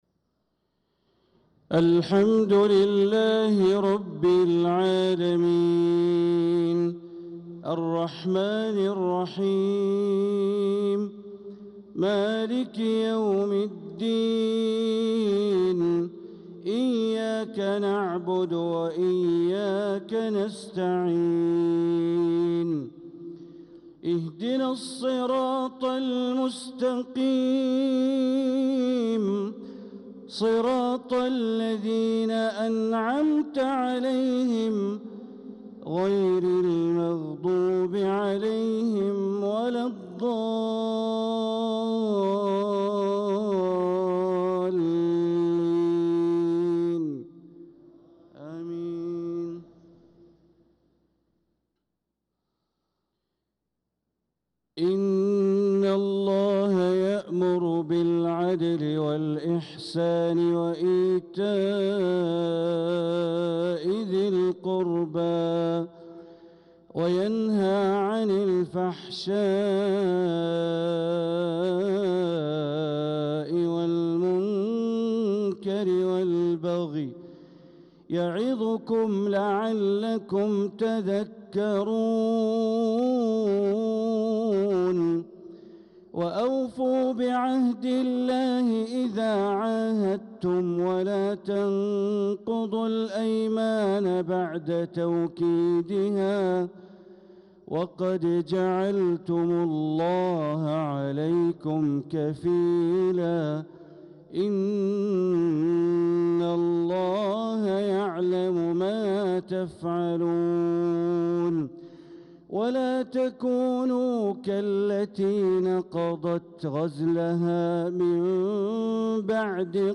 صلاة الفجر للقارئ بندر بليلة 13 ذو الحجة 1445 هـ
تِلَاوَات الْحَرَمَيْن .